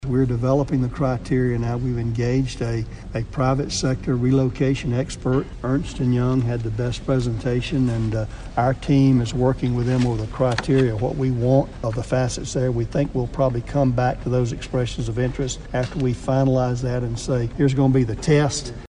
Kansas State welcomed U.S. Agriculture Secretary Sonny Perdue to campus Thursday as the 179th speaker for the Landon Lecture series.